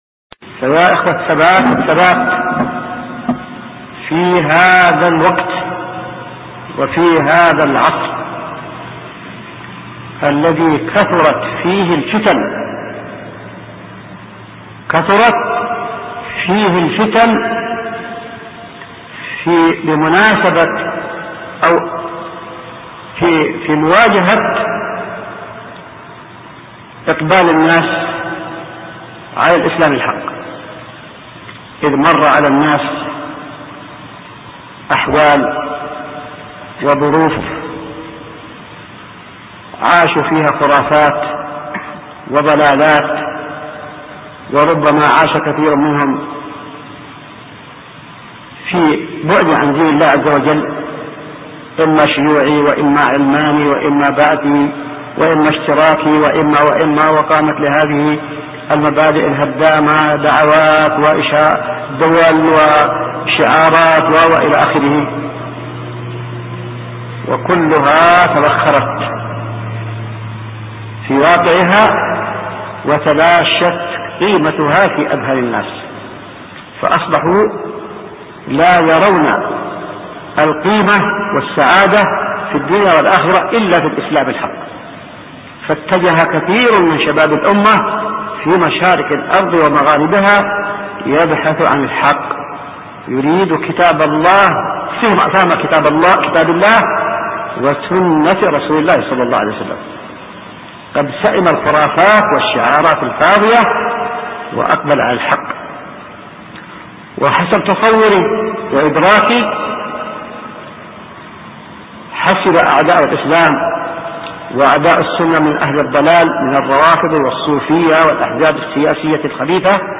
القسم: من مواعظ أهل العلم